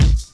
Kick (We Don't Care).wav